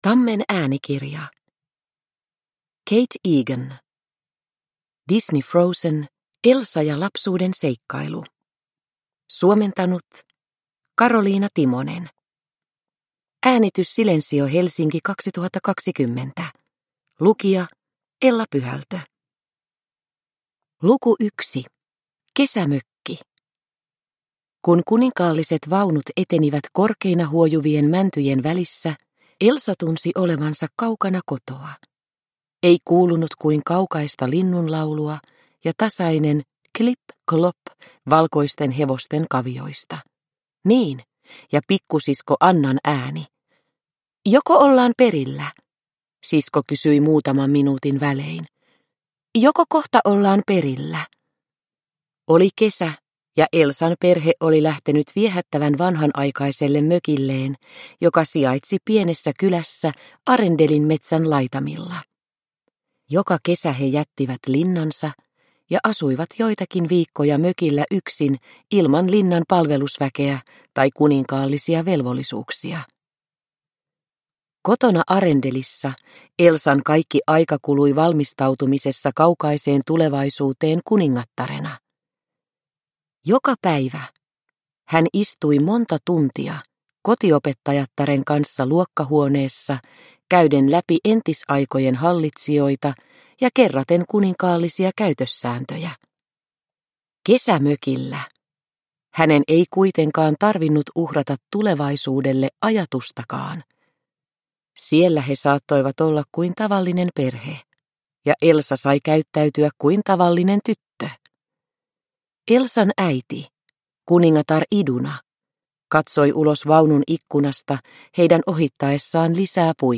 Elsa ja lapsuuden seikkailu – Ljudbok – Laddas ner